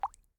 water-drop-01
bath bubble burp click drain drip drop droplet sound effect free sound royalty free Nature